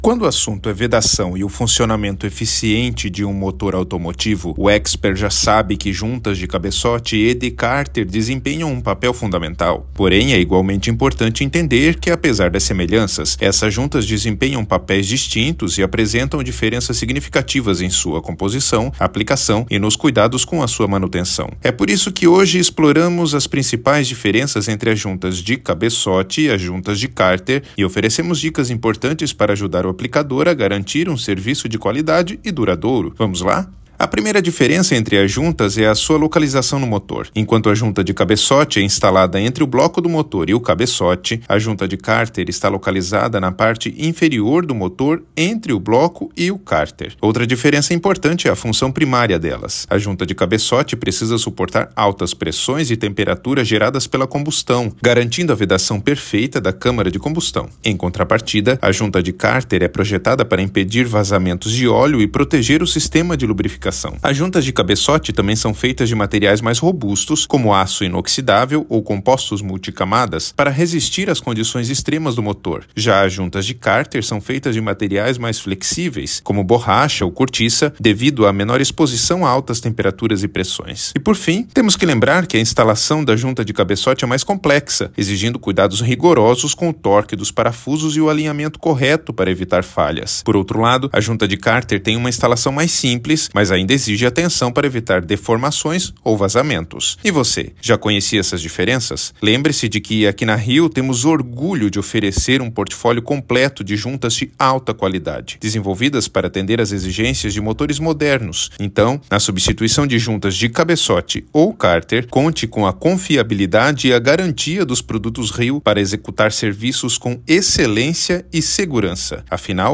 Narracao-03-juntas-de-cabecote.mp3